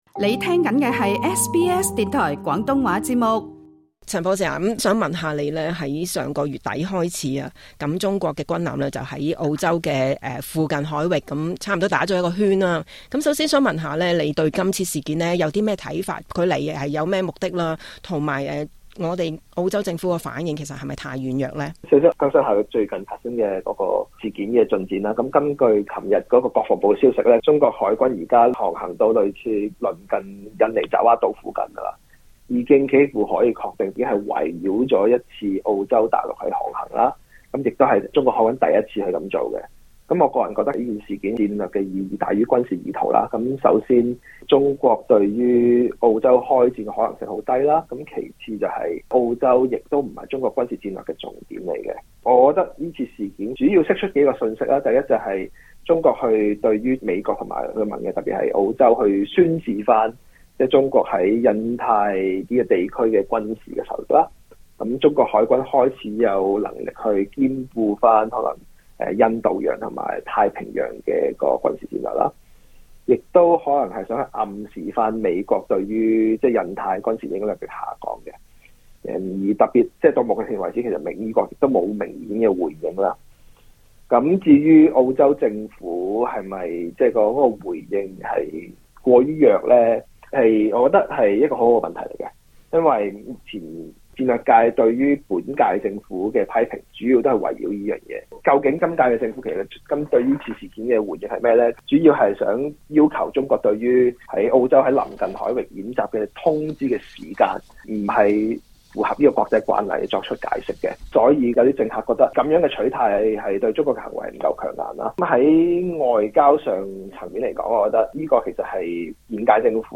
接受SBS廣東話訪問